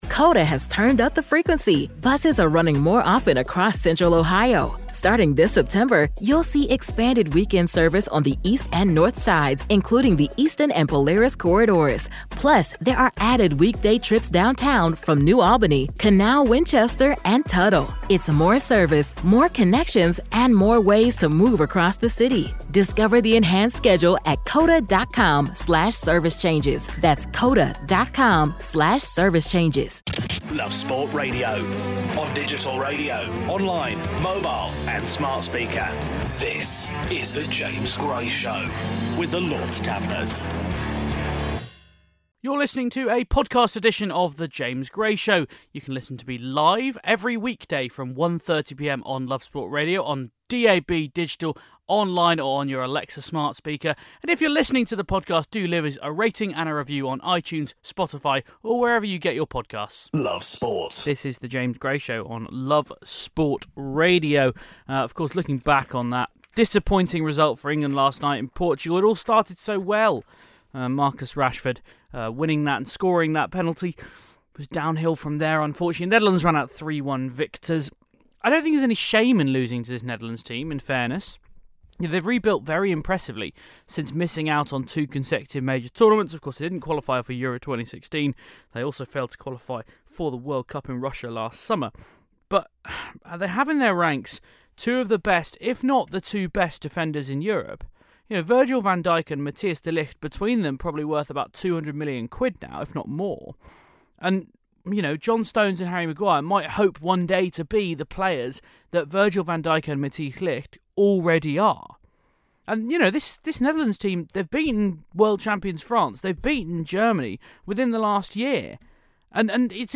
joins us live from Roland Garros